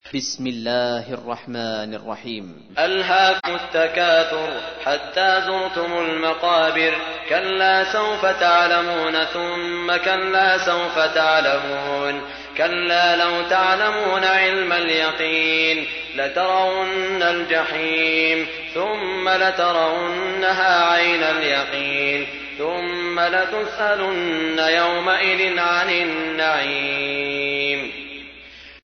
تحميل : 102. سورة التكاثر / القارئ سعود الشريم / القرآن الكريم / موقع يا حسين